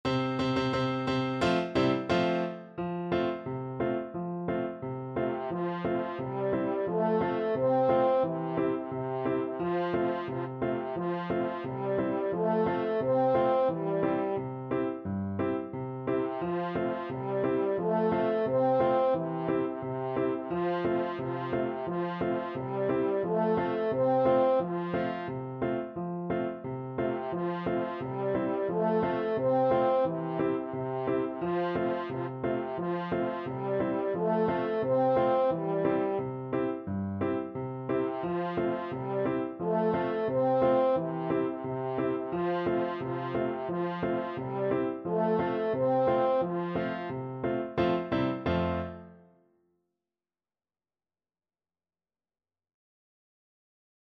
World Europe Switzerland Der Guggug uf em duure Ascht
French Horn
F major (Sounding Pitch) C major (French Horn in F) (View more F major Music for French Horn )
2/4 (View more 2/4 Music)
Steady march =c.88